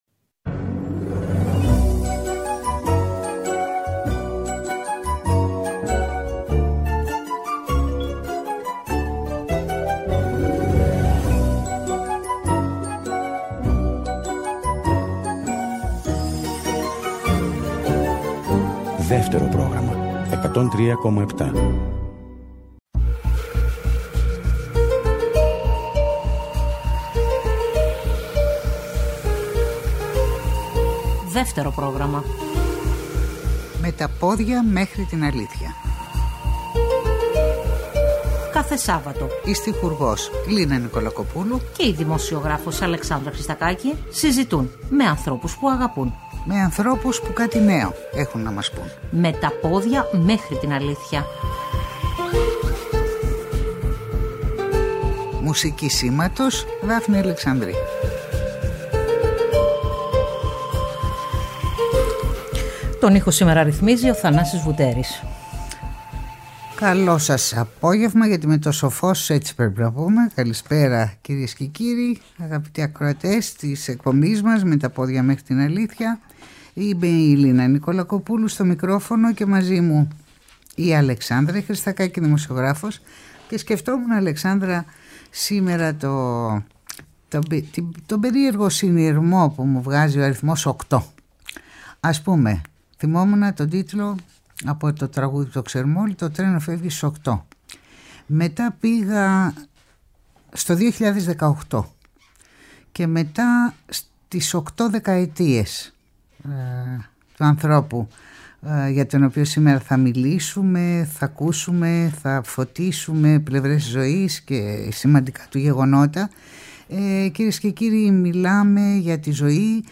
συζητούν με δυο νέους ανθρώπους